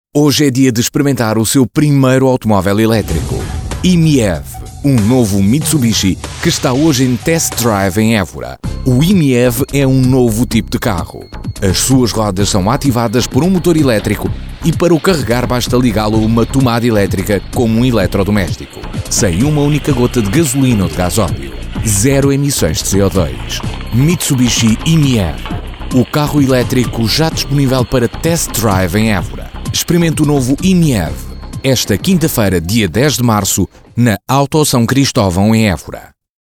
Portuguese Voice Over.
Sprechprobe: eLearning (Muttersprache):